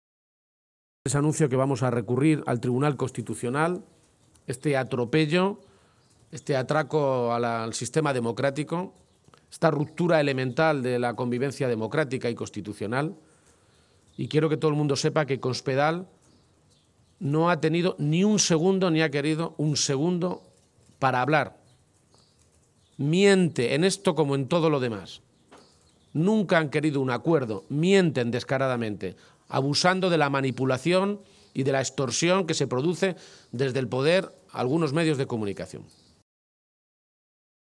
El secretario general del PSOE de Castilla-La Mancha, Emiliano García-Page, ha protagonizado esta mañana un desayuno informativo en Toledo con medios de comunicación en el que ha anunciado la presentación de un recurso ante el Tribunal Constitucional contra la reforma del Estatuto de Autonomía de Castilla-La Mancha aprobada ayer en el Senado solo con los votos del PP “para parar este verdadero atropello, este verdadero pucherazo electoral que quiere dar Cospedal y que es el único motivo de este cambio estatutario”.